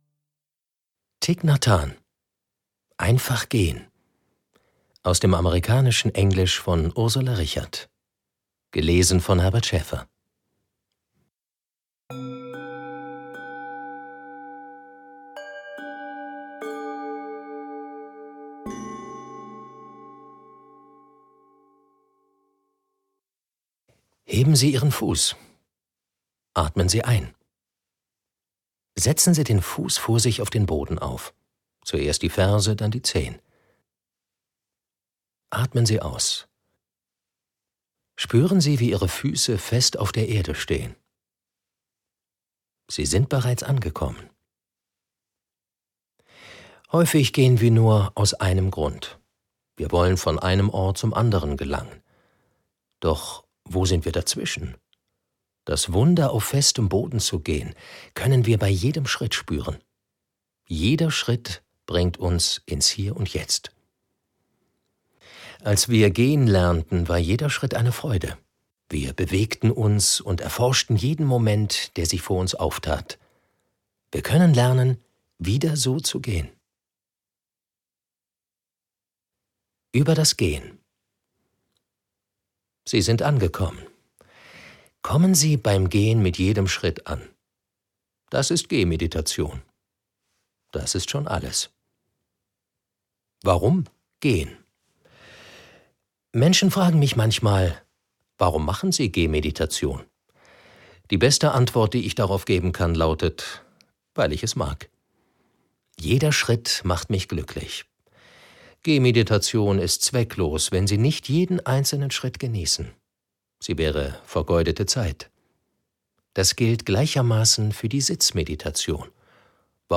Ein kleines Hörbuch mit großer Weisheit - für mehr Frieden in sich selbst und in der Welt.